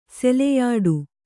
♪ seleyāḍu